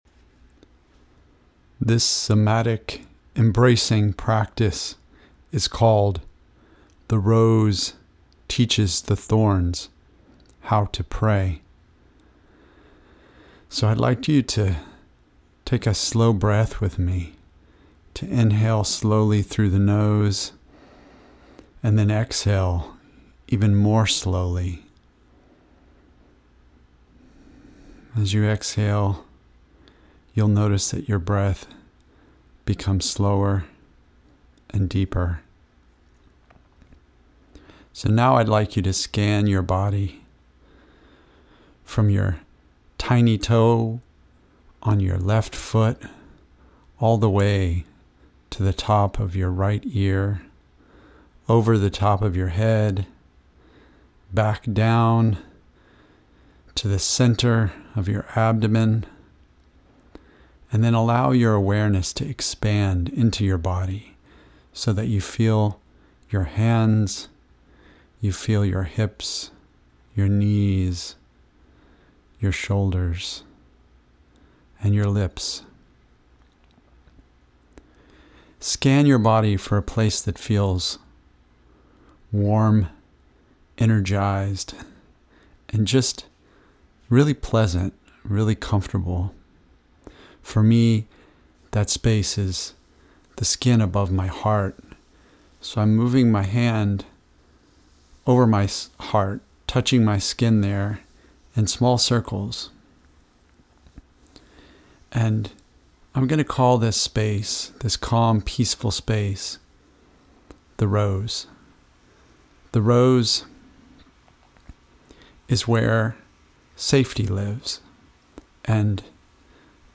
Our Most Powerful Somatic Meditation MP3: The Rose Teaches the Thorns How to Pray